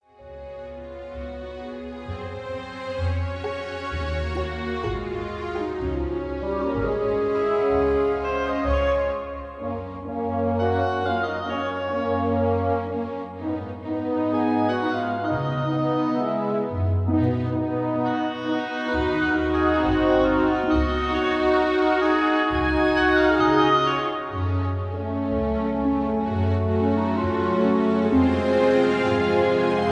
(Key-Db) Karaoke MP3 Backing Tracks